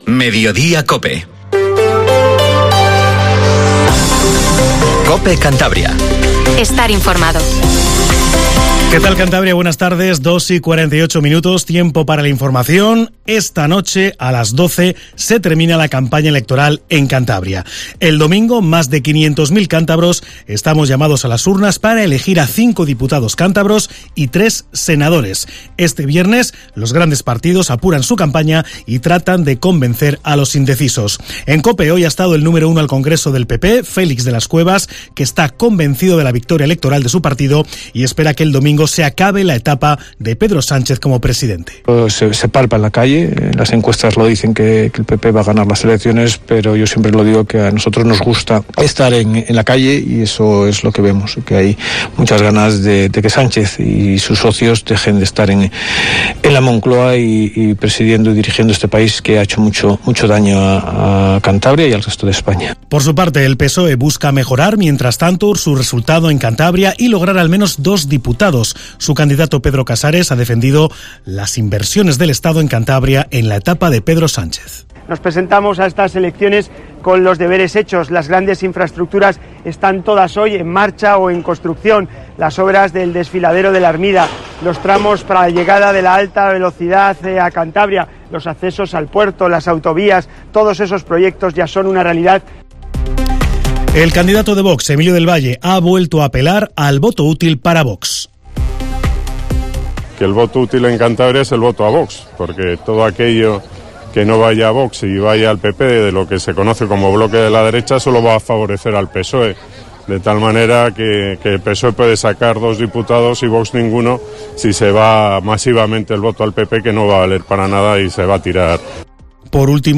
Informativo Mediodía COPE CANTABRIA